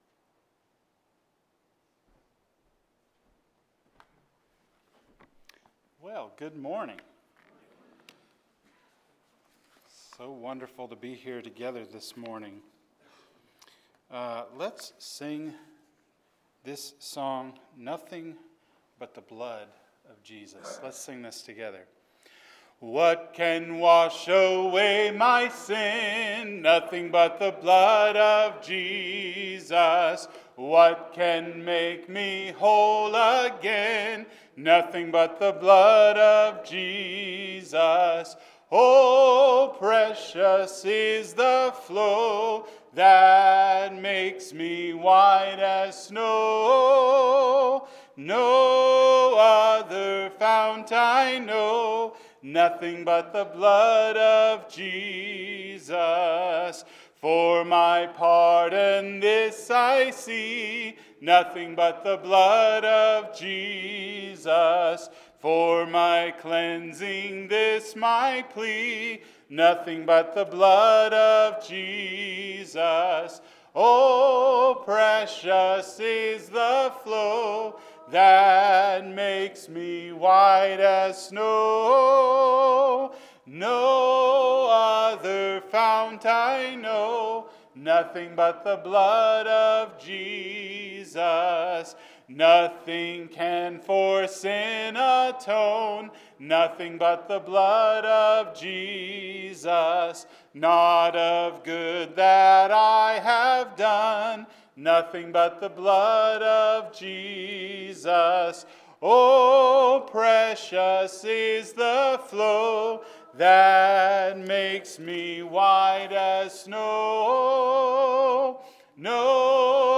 Seven — Completion – Sermon